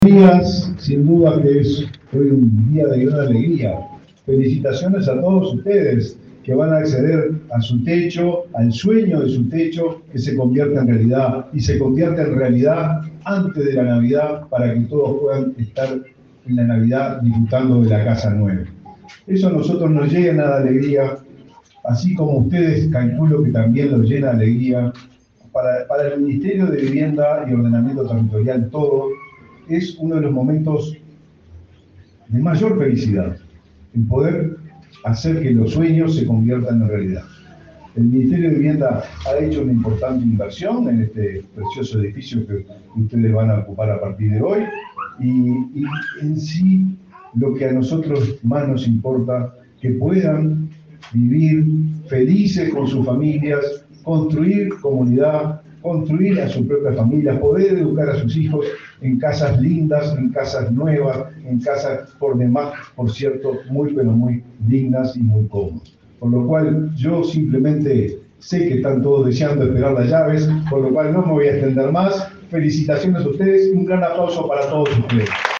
Palabras del ministro de Vivienda, Raúl Lozano
Este lunes 18, el ministro de Vivienda, Raúl Lozano, participó en el acto de entrega de 26 viviendas en la modalidad de alquiler con opción a compra,